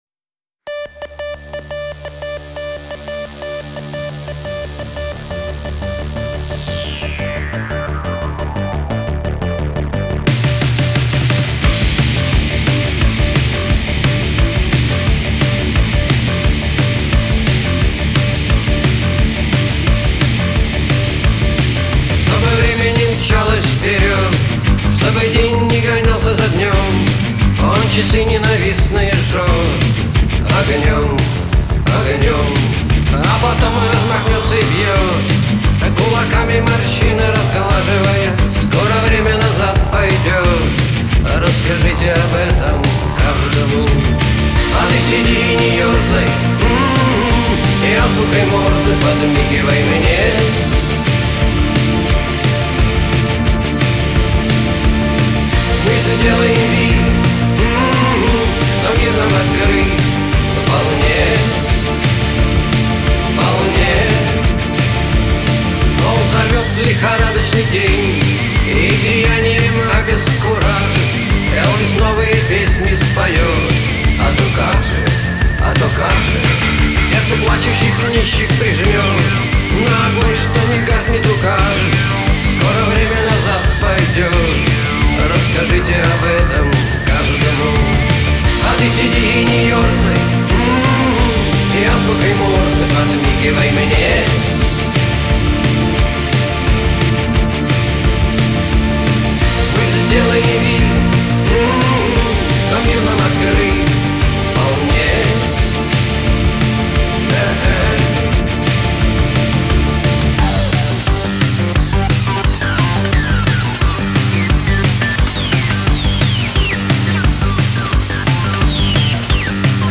Это не оригинал,это ремикс,оригинал мне не очень.